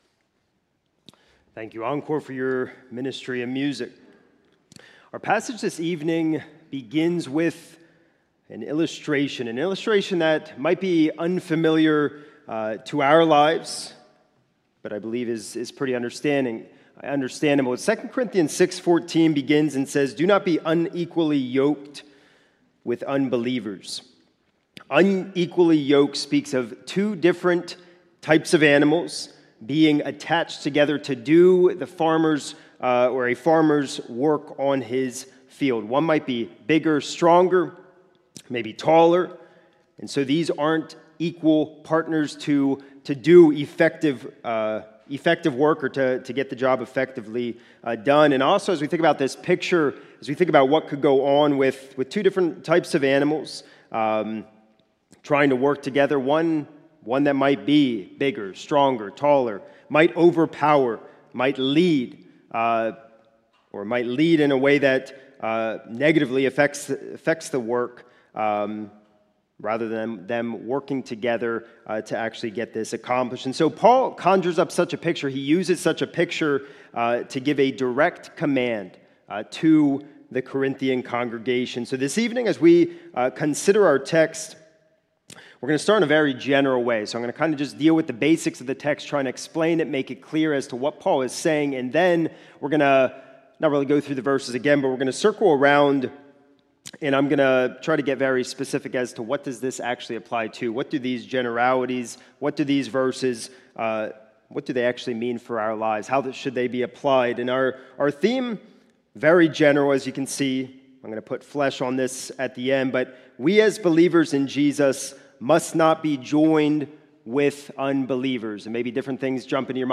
This is a sermon recorded at the Lebanon Bible Fellowship Church in Lebanon, PA during the evening worship service on 3/1/2026